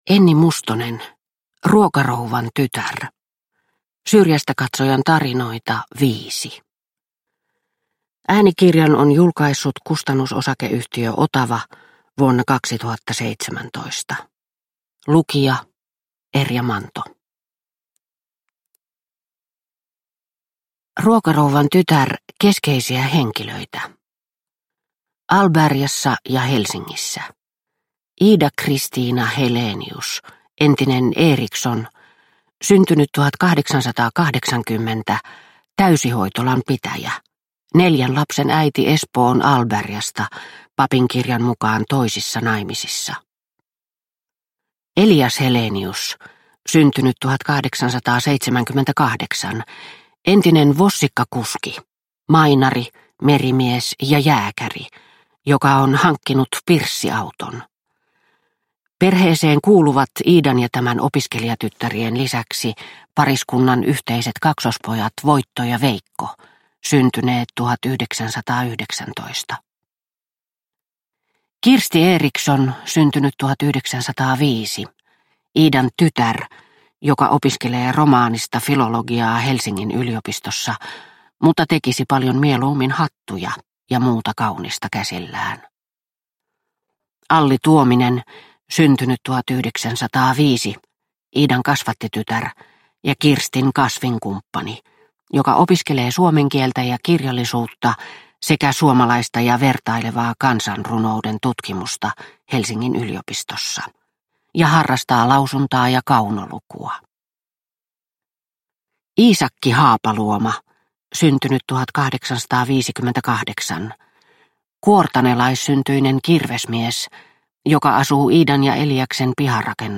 Ruokarouvan tytär – Ljudbok – Laddas ner